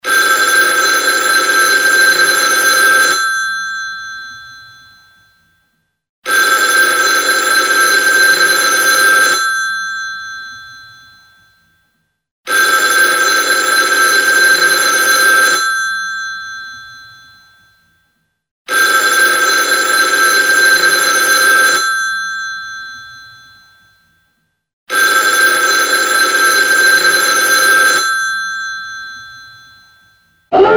Old Phone Long Ring Ring ringtone free download
Message Tones